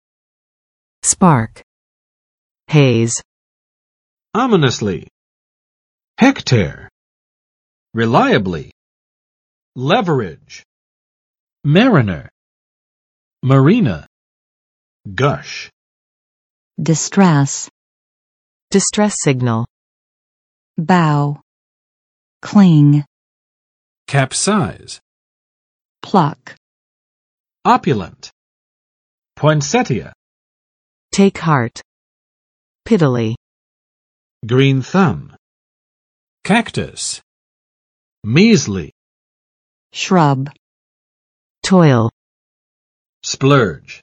[spɑrk] v. 发动；点燃